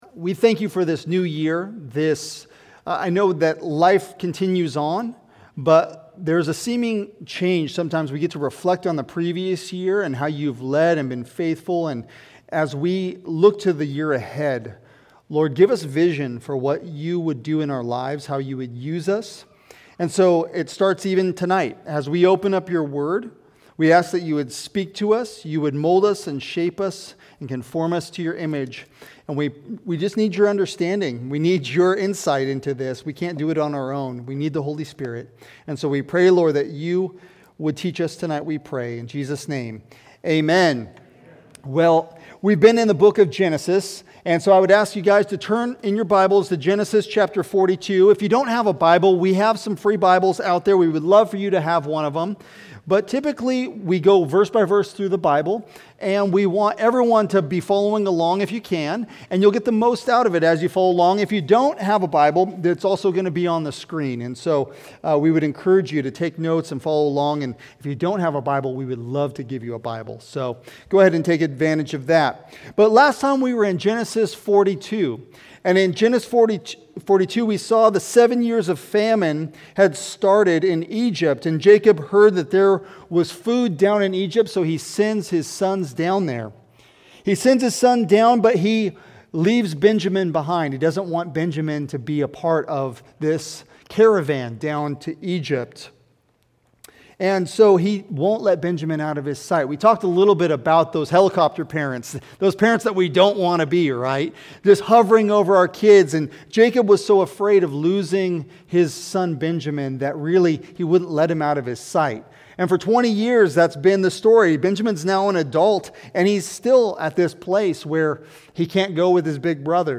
Sermons | Heritage Bible Church of Tri Cities